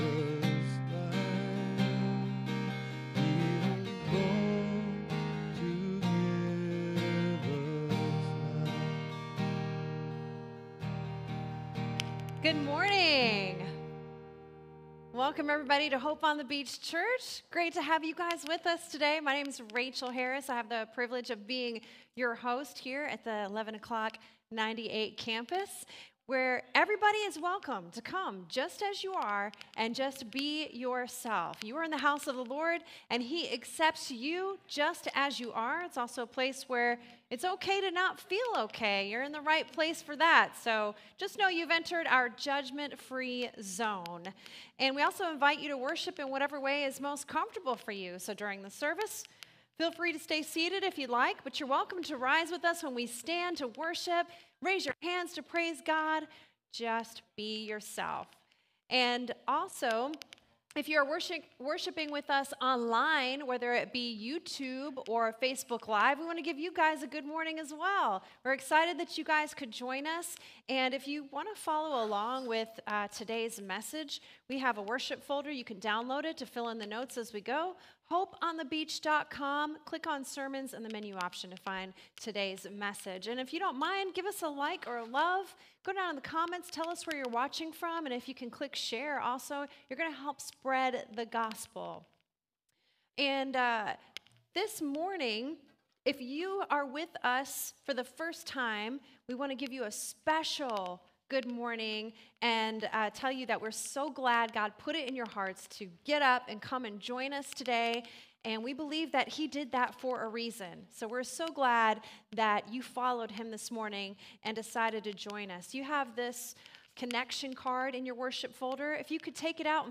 SERMON DESCRIPTION Advent is a time of anticipation, but God’s great gift to us is that the object of our greatest hope has already arrived.